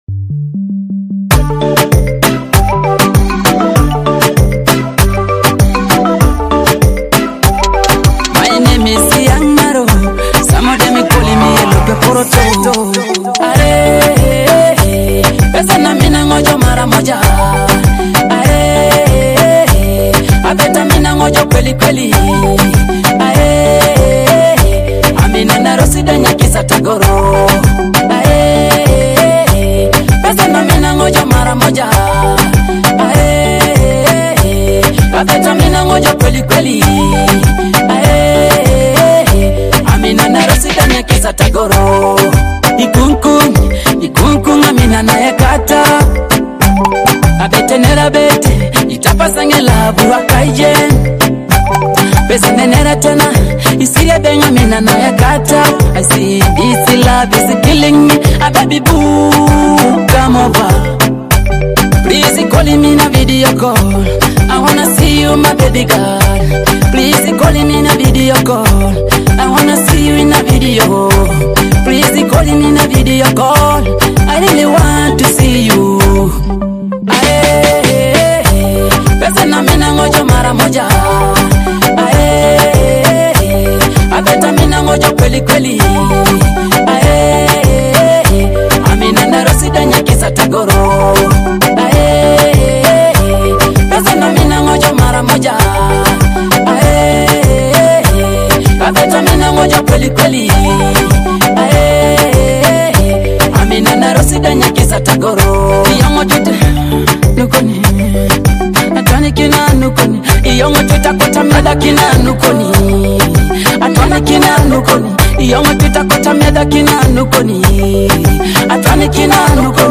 uplifting and romantic Teso music